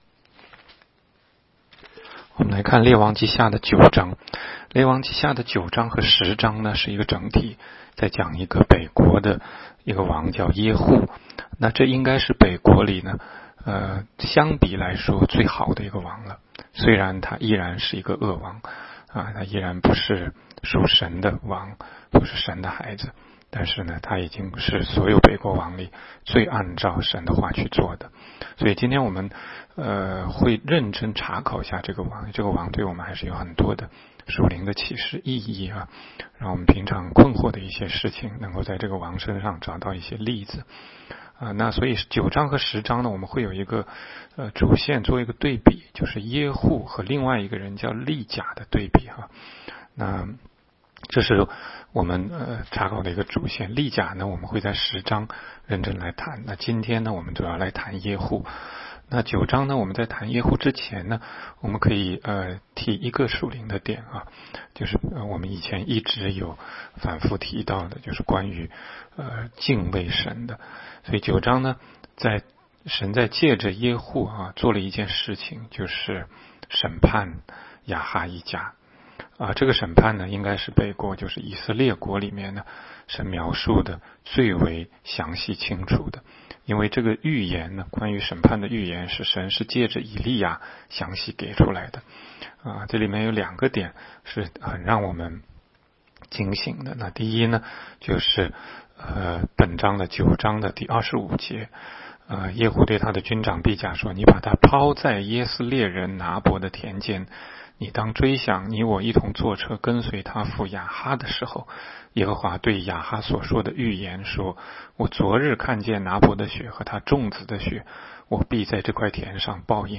16街讲道录音 - 每日读经-《列王纪下》9章